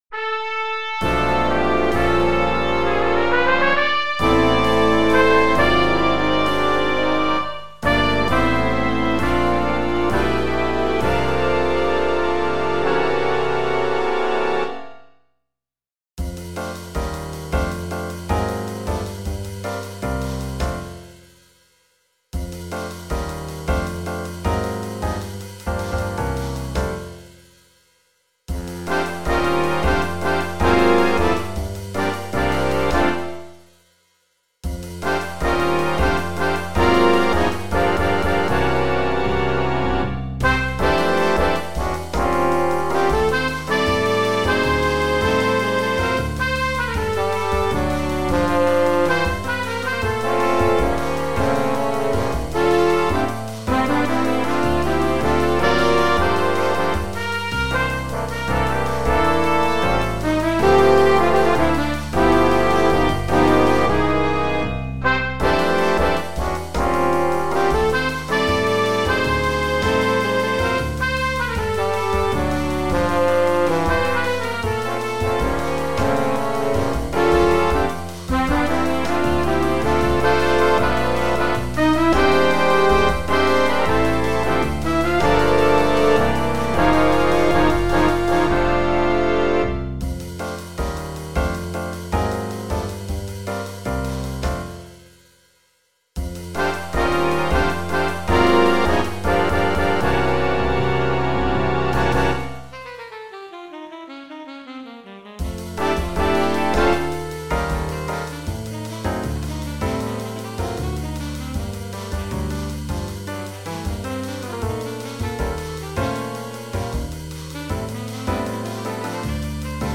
Rehearsal Files for Band Members
SWJ Rehearsal Recordings - Big Band